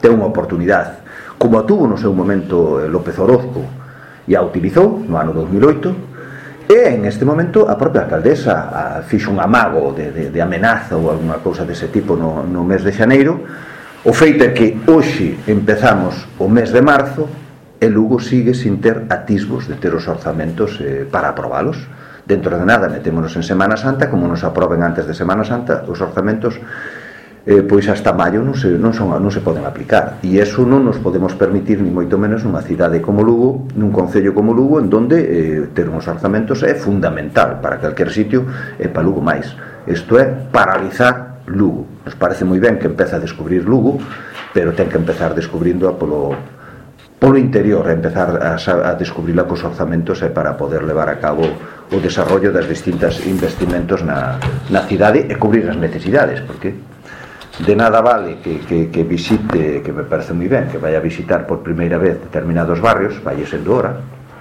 El portavoz del Grupo Municipal del Partido Popular en el Ayuntamiento de Lugo, Jaime Castiñeira, pidió esta mañana en rueda de prensa a la alcaldesa que se someta a una cuestión de confianza.